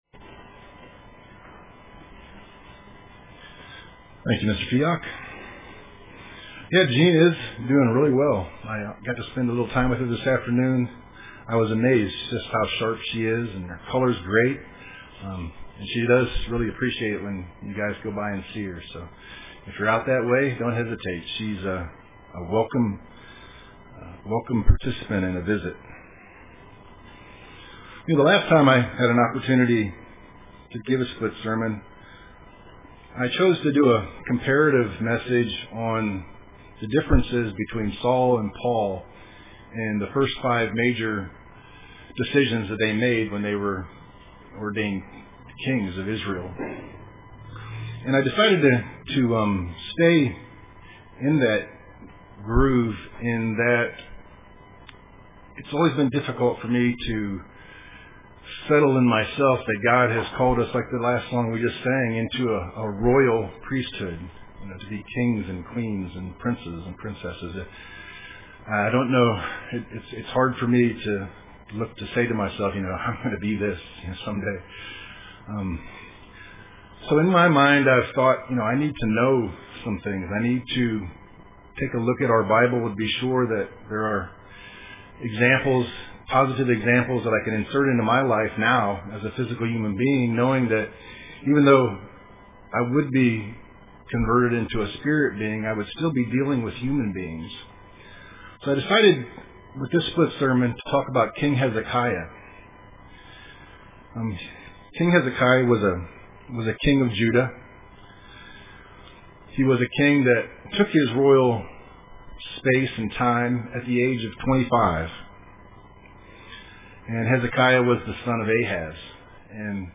Print Hezekiah UCG Sermon Studying the bible?